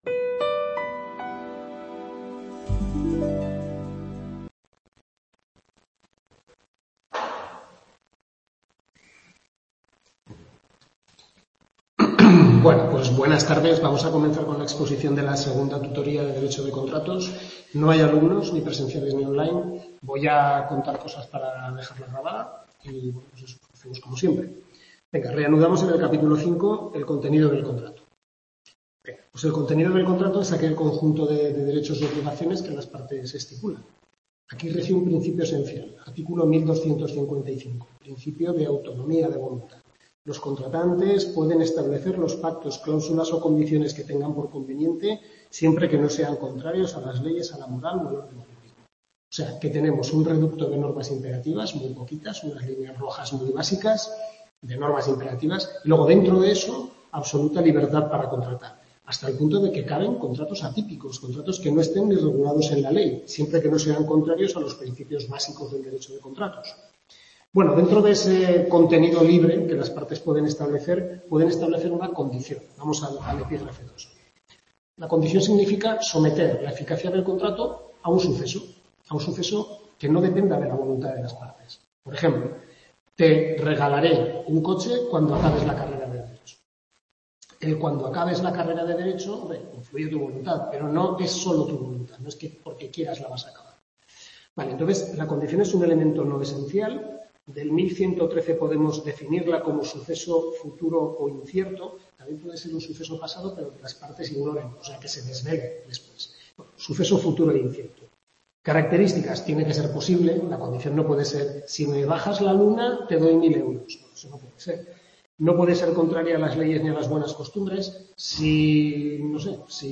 Tutoría 2/6 Derecho de contratos, centro UNED Calatayud, capítulos 5-9 Manual del Profesor Lasarte